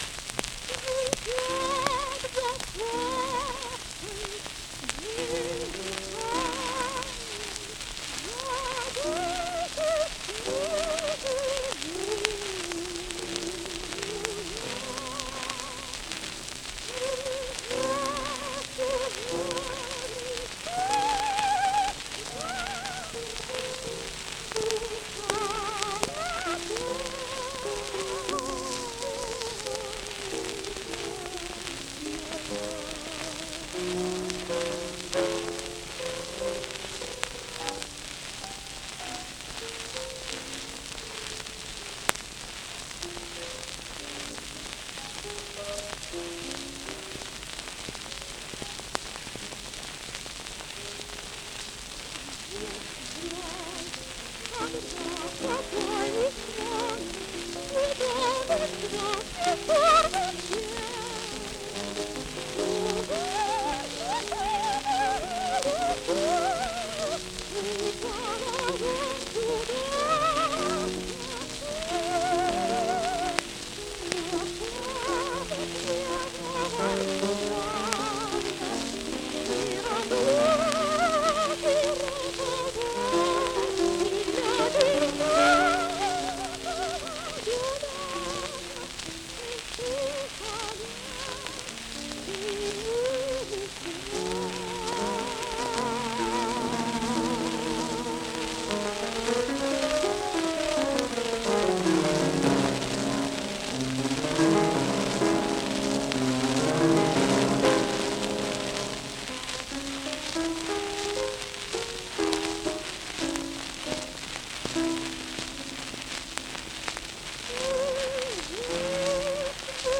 For: Voice (high) and piano